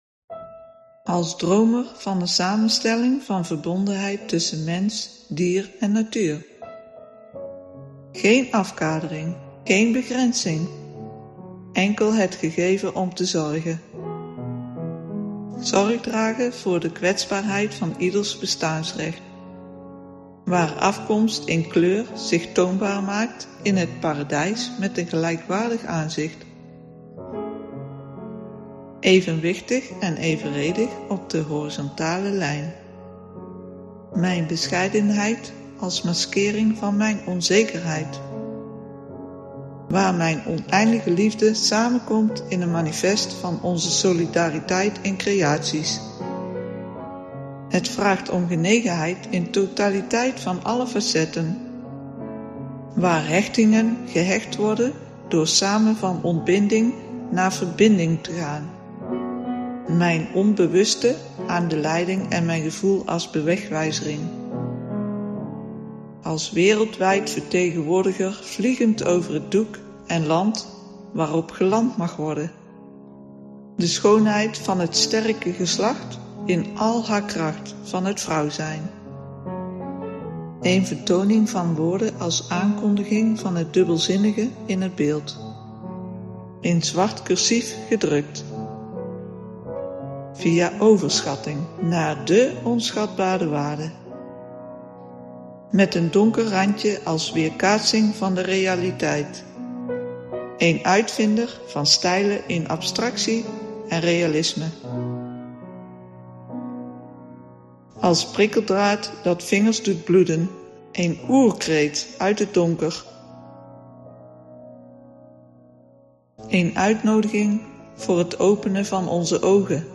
een innerlijke monoloog van een ‘wereldverbeteraar’